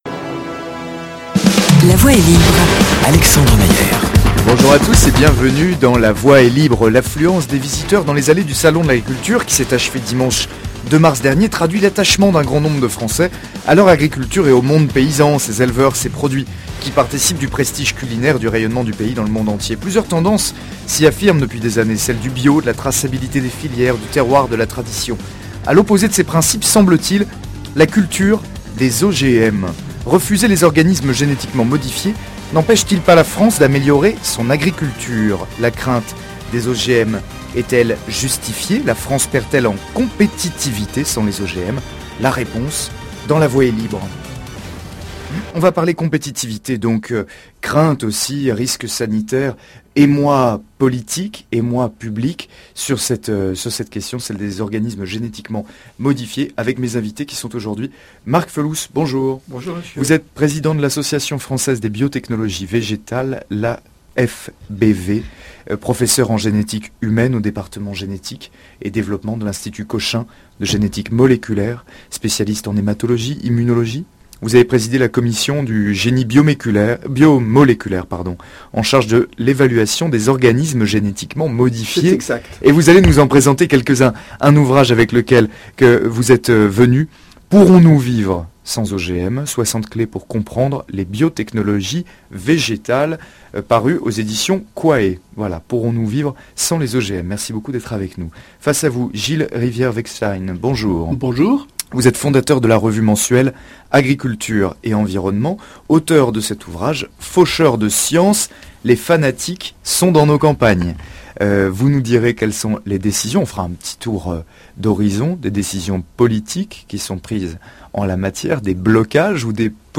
Faut-il interdir les OGM ? débat